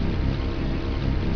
bee.wav